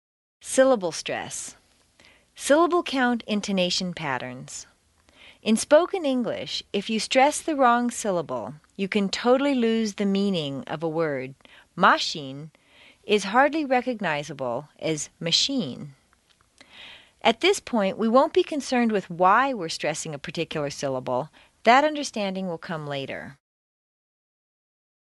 美语口语发音训练 第一册31